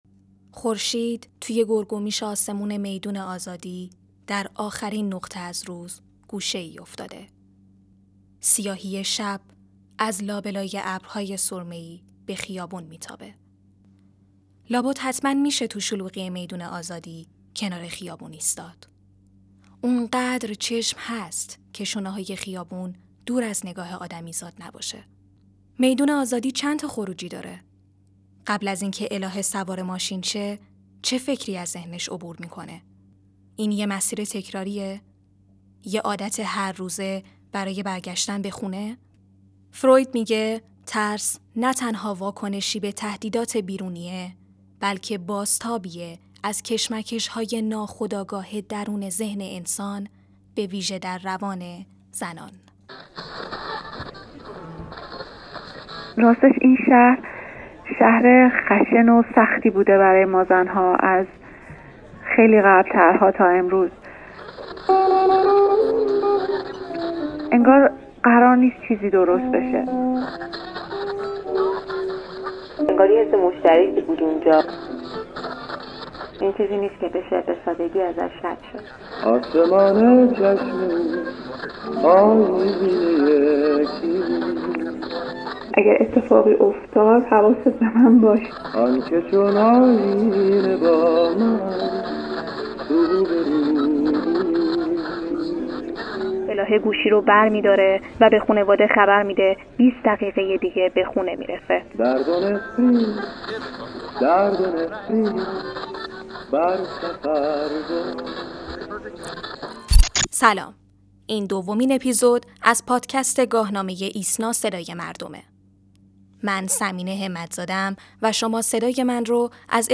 در این قسمت ما از بانوان ایسنا خواسته‌ایم